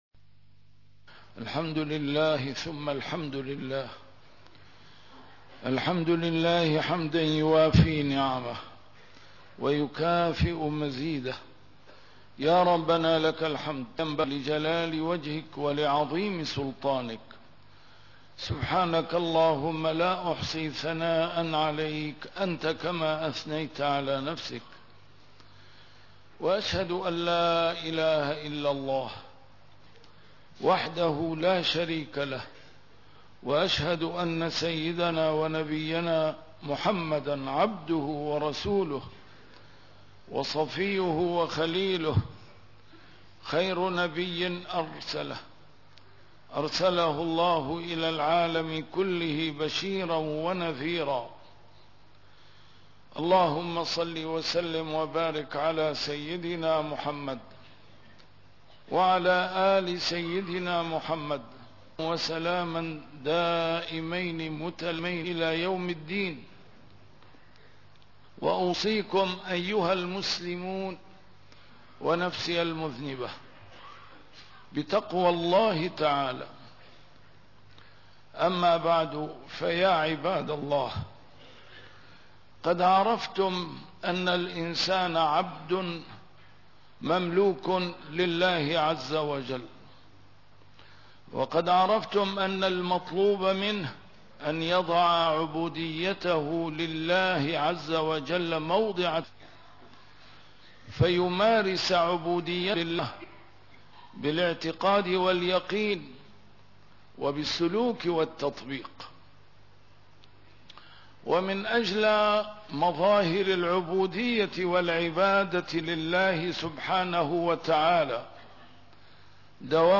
A MARTYR SCHOLAR: IMAM MUHAMMAD SAEED RAMADAN AL-BOUTI - الخطب - وَاللَّهُ غالِبٌ عَلَى أَمْرِهِ وَلَكِنَّ أَكْثَرَ النّاسِ لا يَعْلَمُونَ